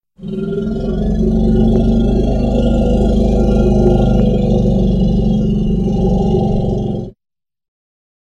elephant-shouting-voice